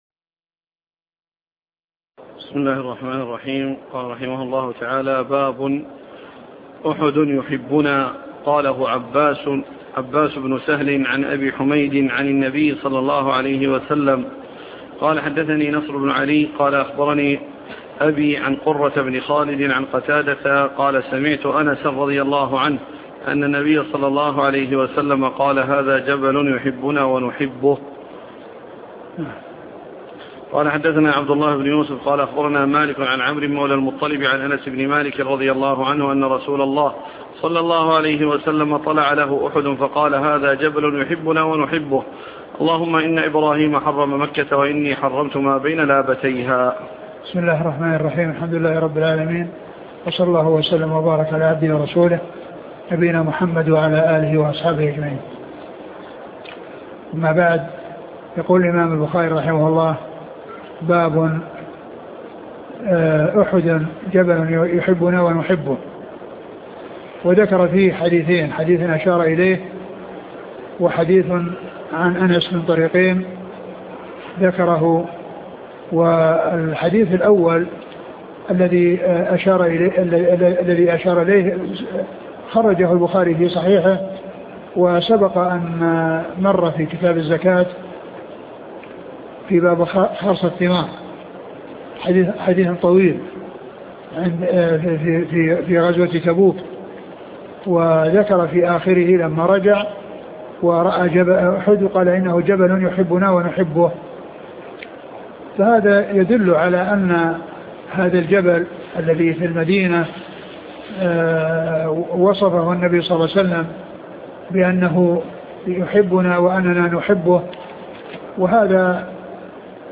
شرح صحيح البخاري الدرس عدد 422